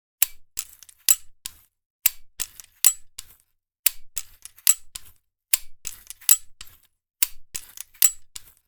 Rotating Sprinkler Loop, Garden, Outdoor 5 Sound Effect Download | Gfx Sounds
Rotating-sprinkler-loop-garden-outdoor-5.mp3